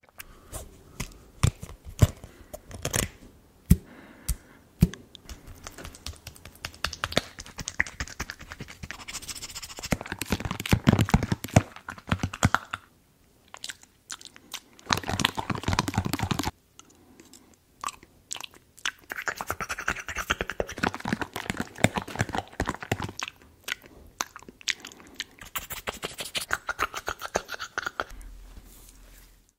Звуки мизофонии
Звук перемешивания майонеза в банке